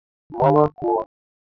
Ниже приведены временные реализации слова при разных условиях
Воспроизводятся только те частоты с номерами 0-120, которые имеют локальные максимумы.